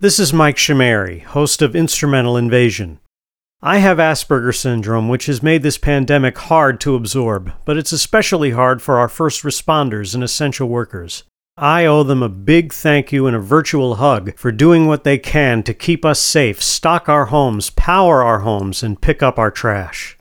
POSTSCRIPT: During the height of the pandemic, WCWP hosts were asked to record messages to include in PSAs (public service announcements).